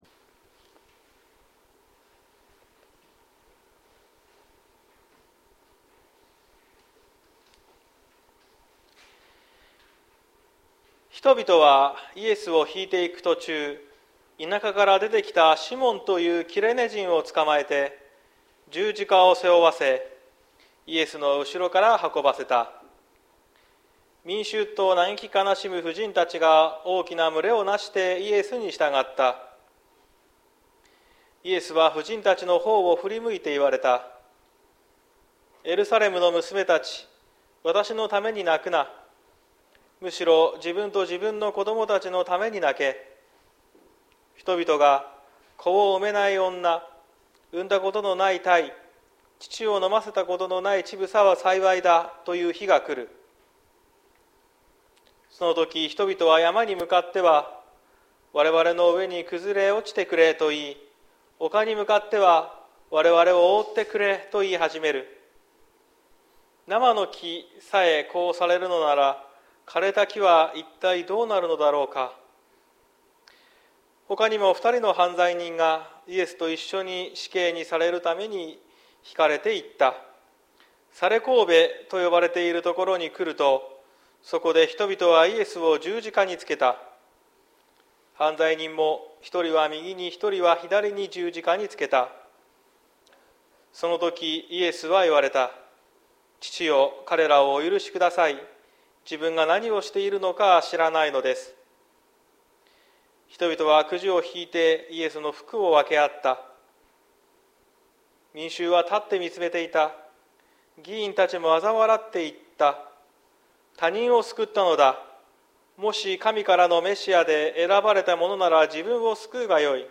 2023年06月25日朝の礼拝「あなたは今、楽園にいる」綱島教会
説教アーカイブ。